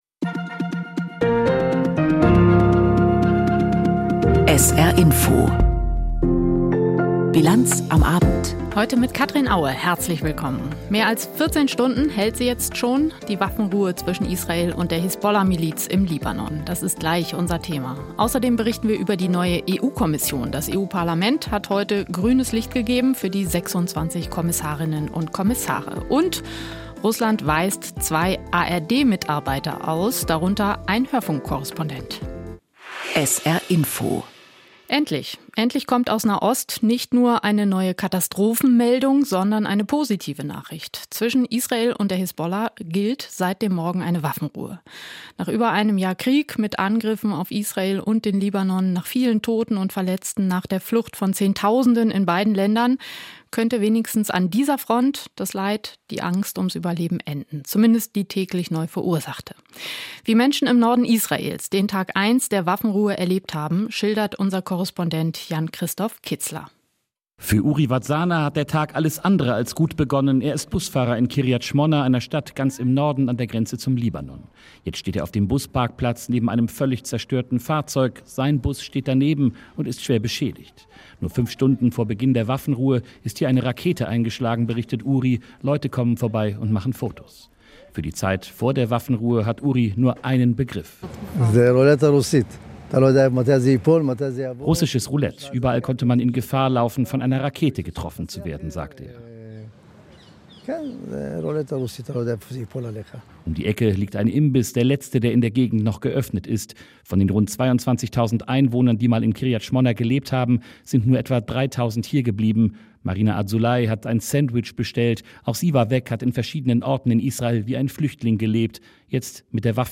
Aktuelles und Hintergründe zu Entwicklungen und Themen des Tages aus Politik, Wirtschaft, Kultur und Gesellschaft in Berichten und Kommentaren.